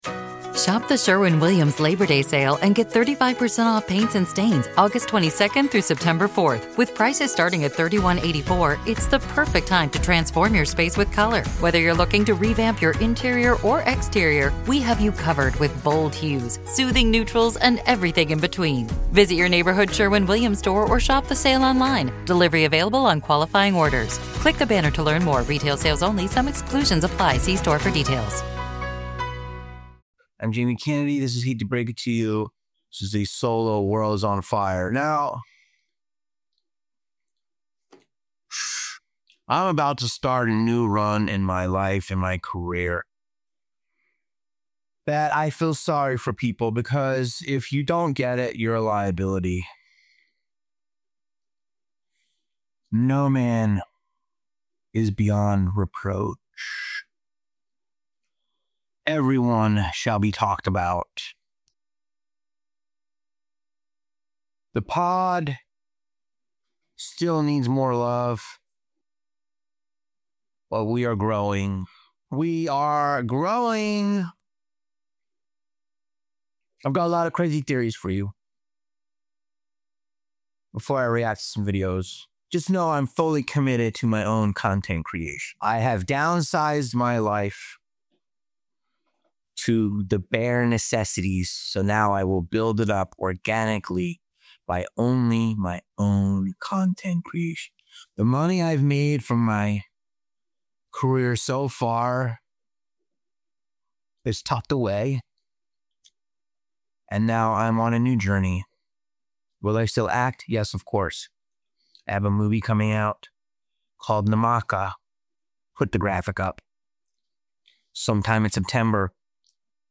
1 The Cost of Childhood Stardom and Staying Sane in Hollywood with Raven-Symoné | Ep 229 HTBITY 1:24:28 Play Pause 7d ago 1:24:28 Play Pause Play later Play later Lists Like Liked 1:24:28 Raven-Symoné stopped by the studio, and from the moment we sat down it felt like two people comparing notes on the same Hollywood madness. We talk about what it was like for her to grow up in the spotlight, from The Cosby Show to That’s So Raven, and how she’s managed to stay grounded while navigating fame, identity, and creativity in an industry t… … continue reading